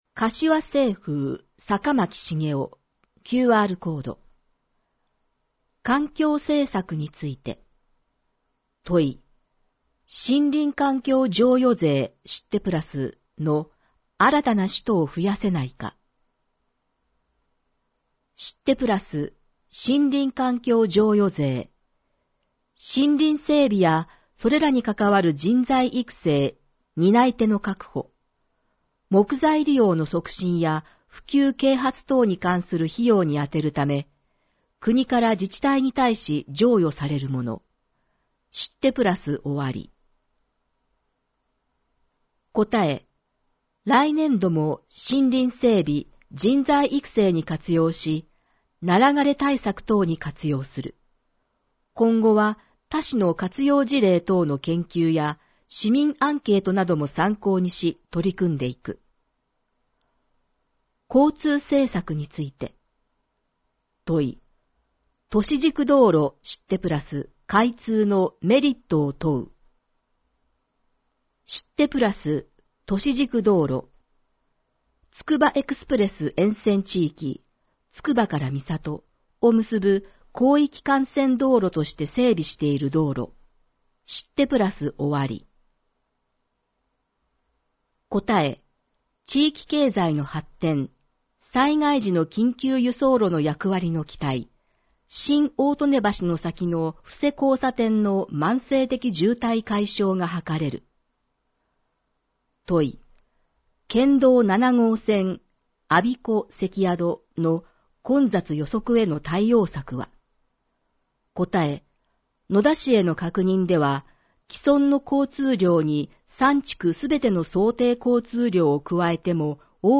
• かしわ市議会だよりの内容を音声で収録した「かしわ市議会だより音訳版」を発行しています。
• 発行は、柏市朗読奉仕サークル（外部サイトへリンク）にご協力いただき、毎号行っています。